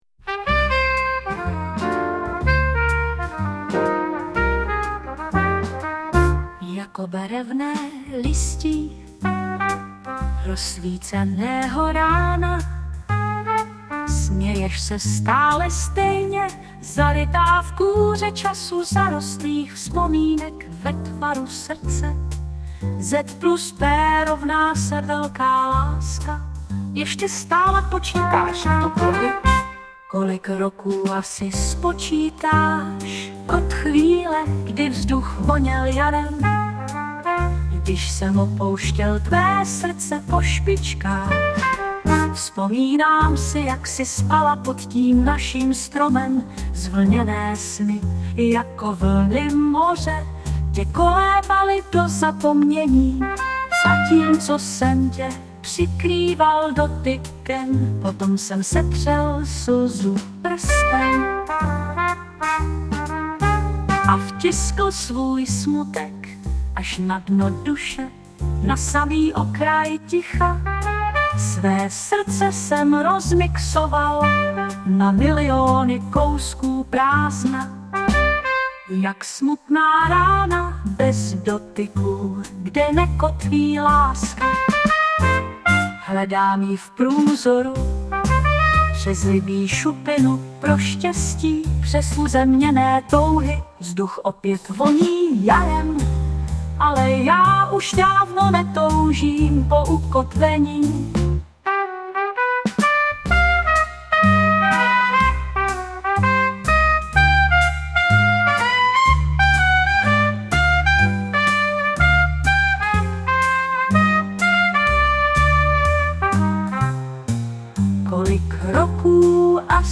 2016 & Hudba a Zpěv: AI